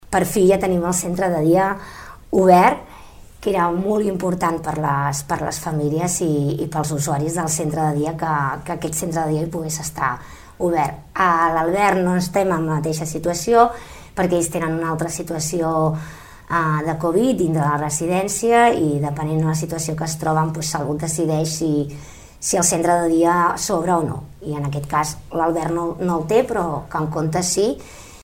El control de la situació sanitària ha permès reobrir el Centre de Dia de Can Comte. Un servei que, per decisions pròpies, no està obert a la Residència l’Albert. Ho detalla la regidora Toñi Garcia.